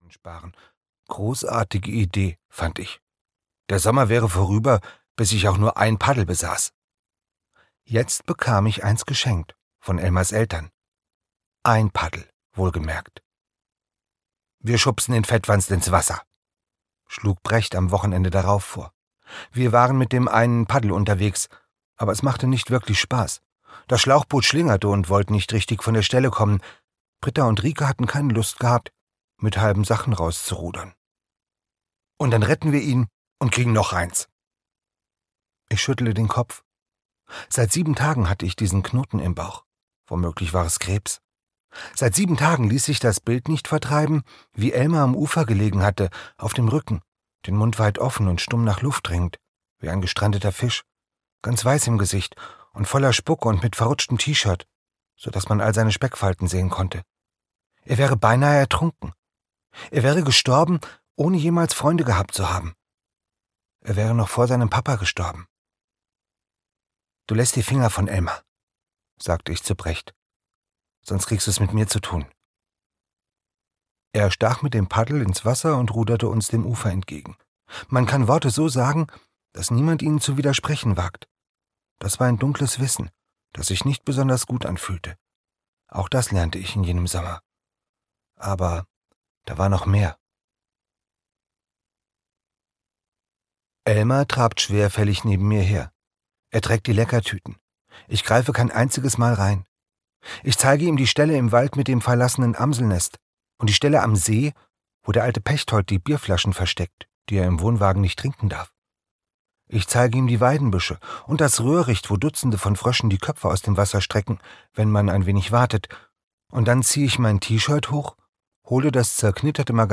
Froschmaul-Geschichten - Andreas Steinhöfel - Hörbuch